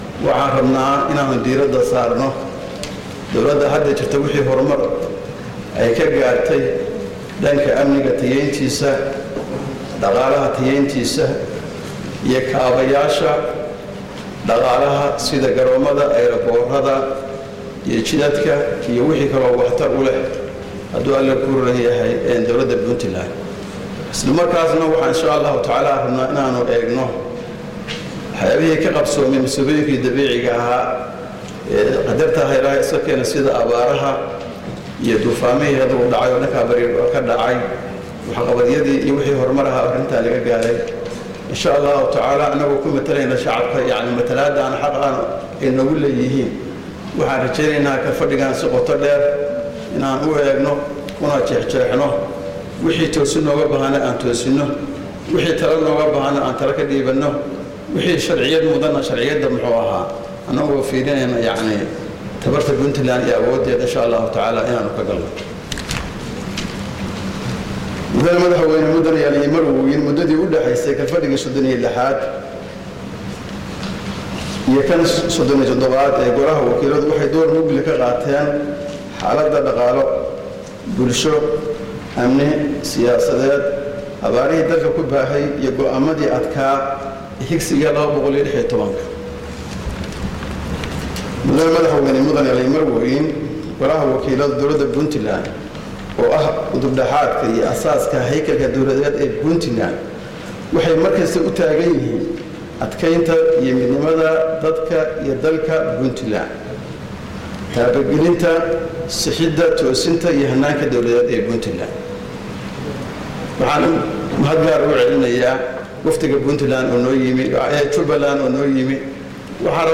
Dhagayso Gudoomiye kuxigeenka Baarlamaanka oo ka hadlaya